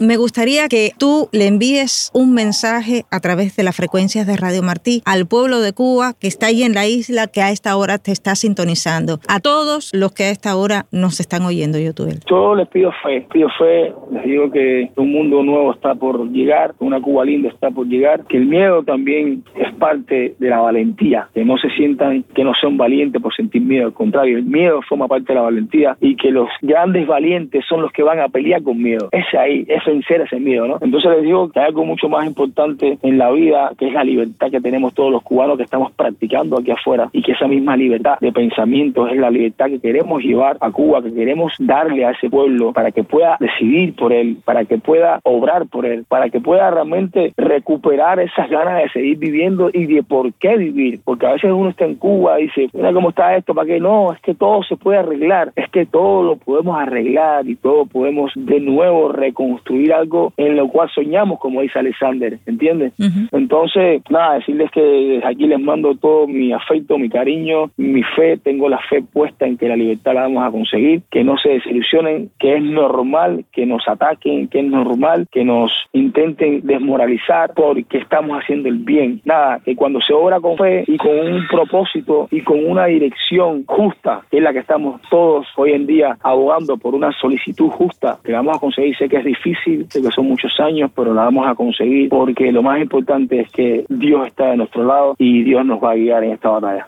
El cantante Yotuel Romero envió un mensaje de esperanza al pueblo de Cuba, a través de las frecuencias de Radio Martí.
Declaraciones de Yotuel Romero a Radio Televisión Martí